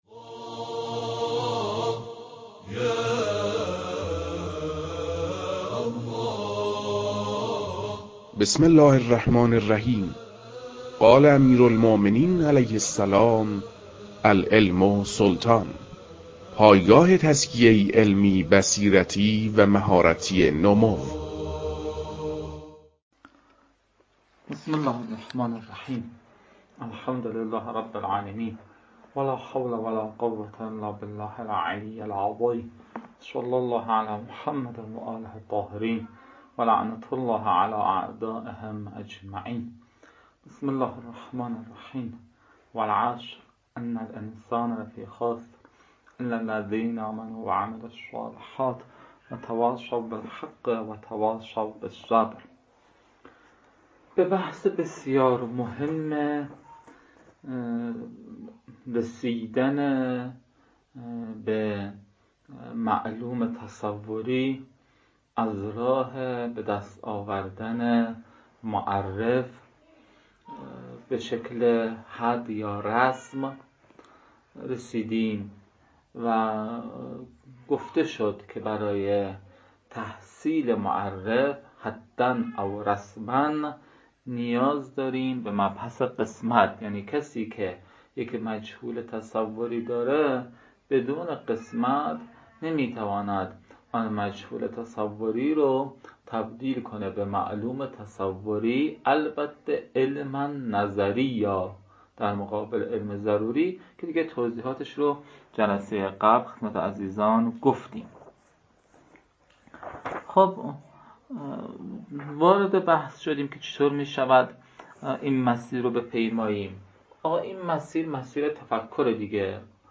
در این بخش، کتاب «منطق مظفر» که اولین کتاب در مرحلۀ شناخت علم منطق است، به صورت ترتیب مباحث کتاب، تدریس می‌شود.